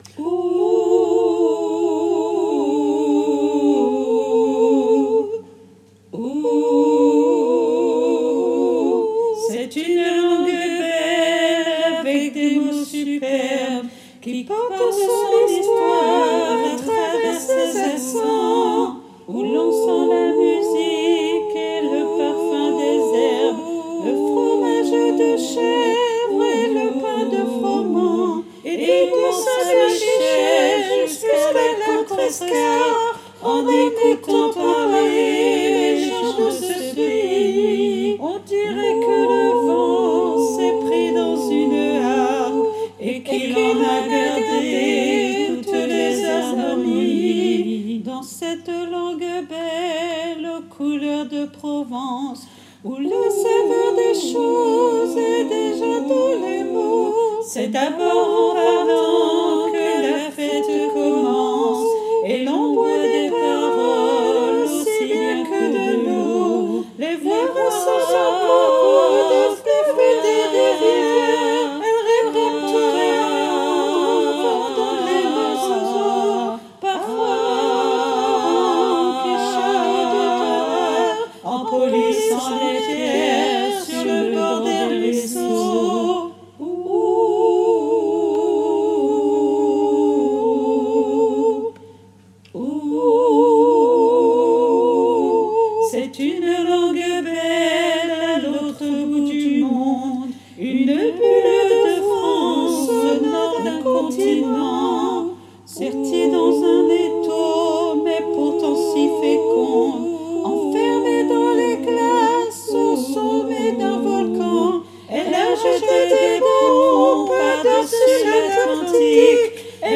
MP3 versions chantées
Tutti